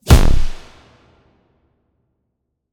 TM88 FunkKick6.wav